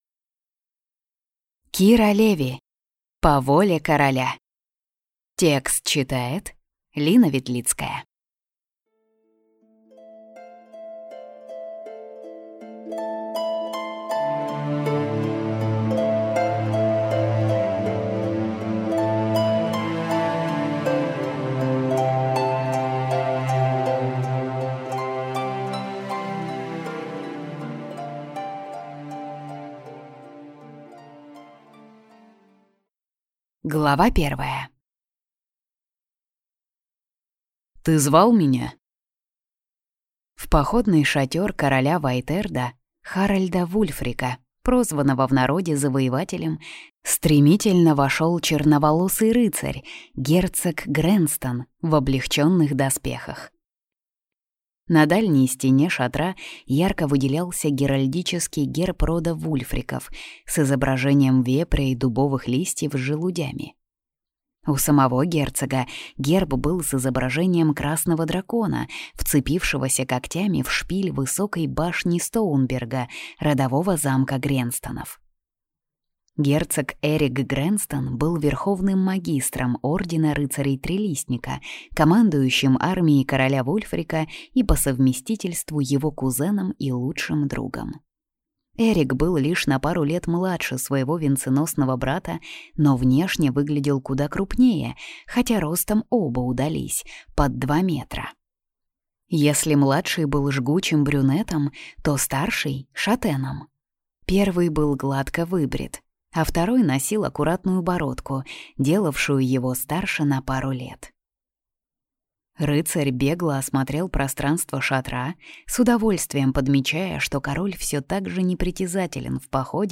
Aудиокнига По воле короля